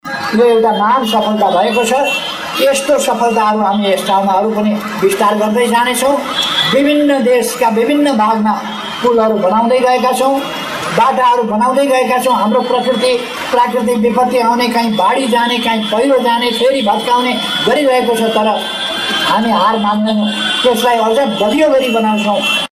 प्रधानमन्त्री ओलीले उद्घाटनको क्रममा ग्वार्को ओभरपासलाई ‘देश विकासको नयाँ चरण’ भनी व्याख्या गरे पनि उनको भाषणको अर्को अंश अझै विवादित बन्न पुगेको छ।